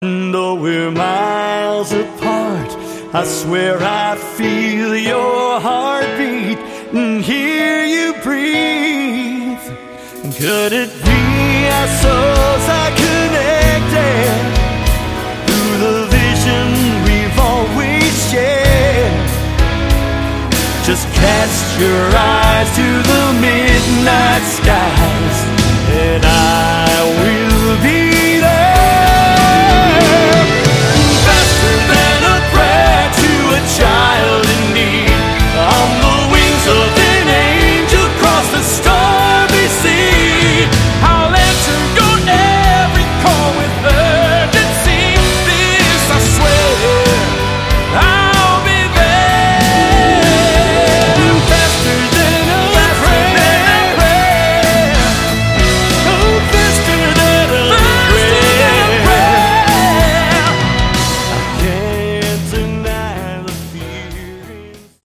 Category: AOR
vocals, guitar, keyboards
drums
bass